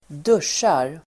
Uttal: [²d'usj:ar]